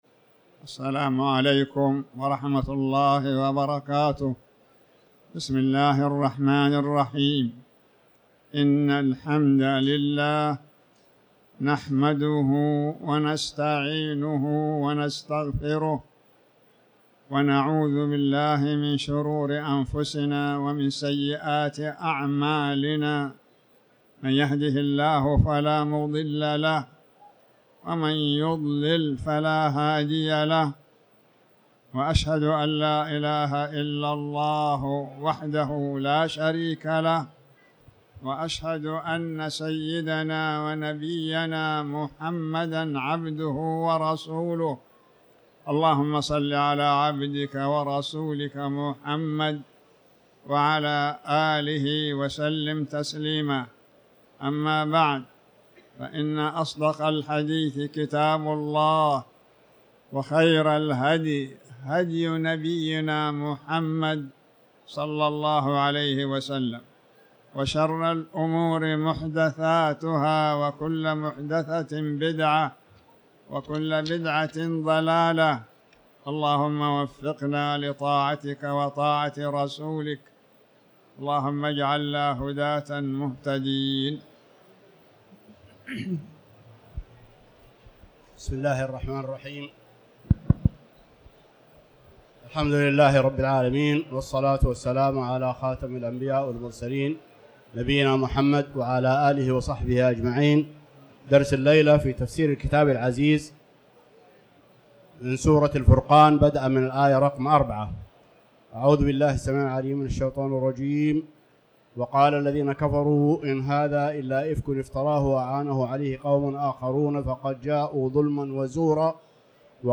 تاريخ النشر ٢٠ جمادى الآخرة ١٤٤٠ هـ المكان: المسجد الحرام الشيخ